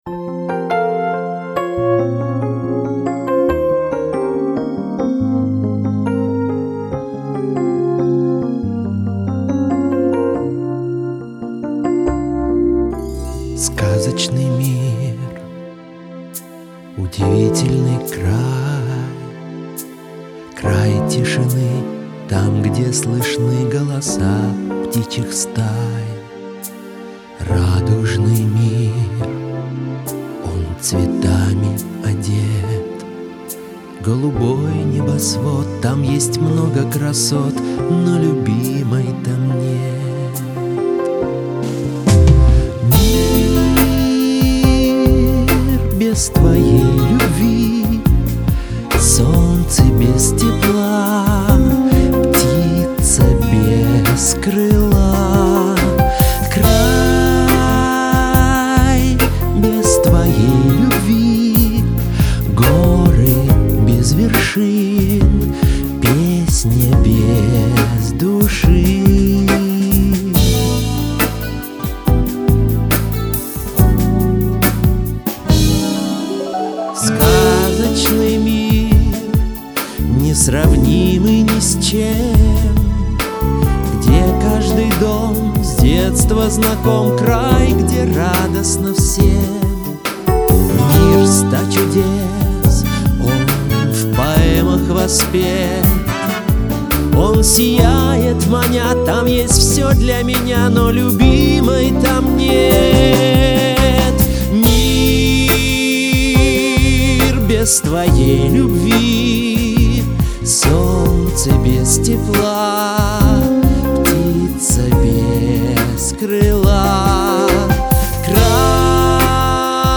Спели очень сладенько, слащаво даже.